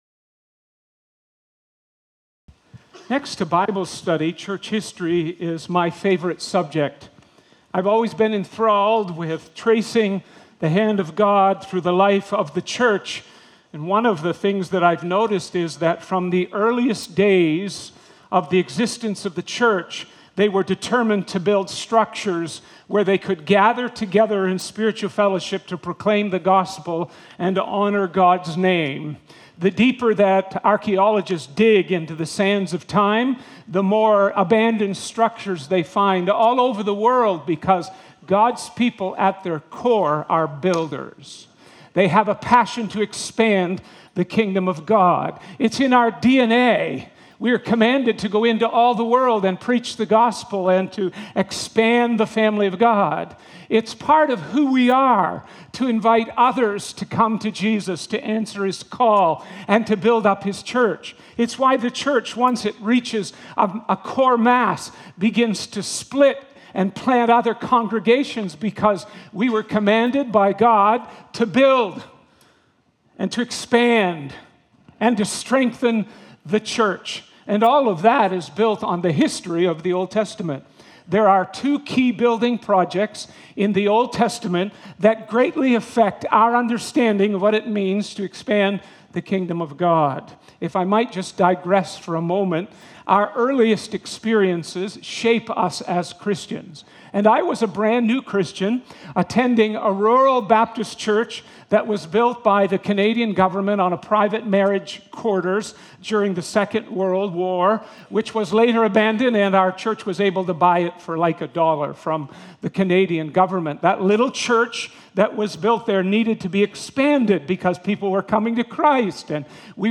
Through this sermon, we were reminded that everything we possess is ultimately rooted in God’s gracious rule and purposeful design.